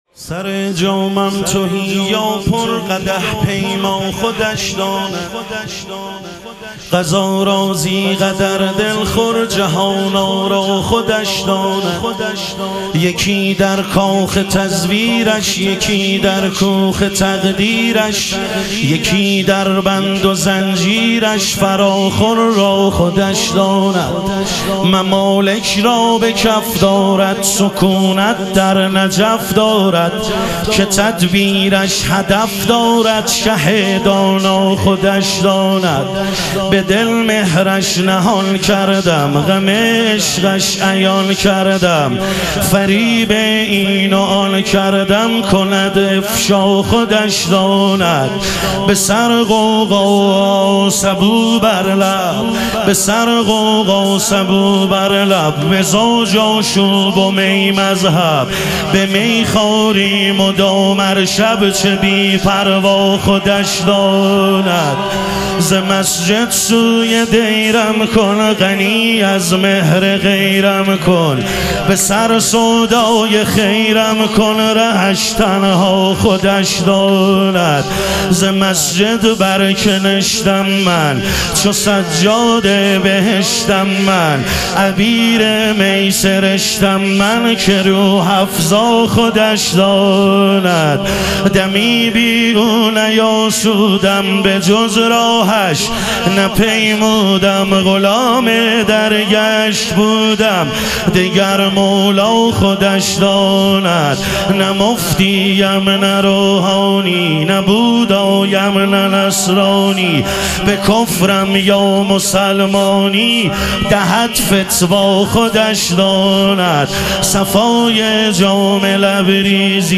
مدح و رجز
شب ظهور وجود مقدس حضرت مهدی علیه السلام